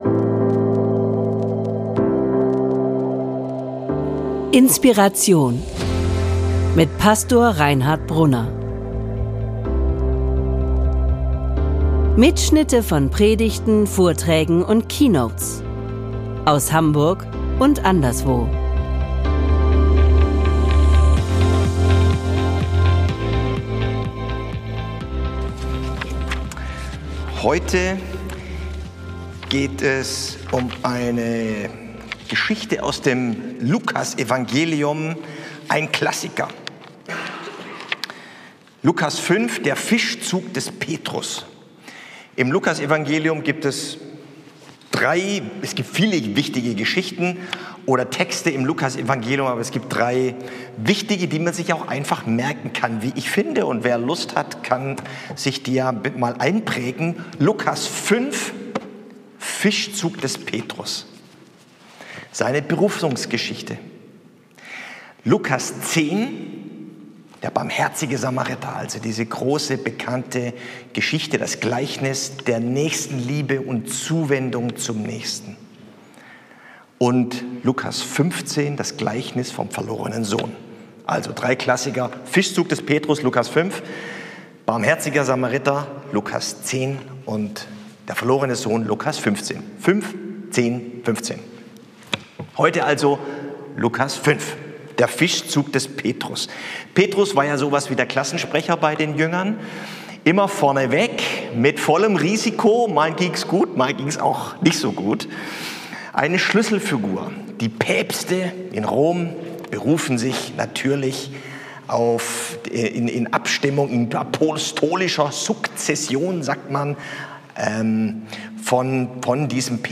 Fahr noch einmal hinaus und wirf deine Netze aus - Wir wir anfangen können, zu glauben - ~ INSPIRATION - Predigten und Keynotes Podcast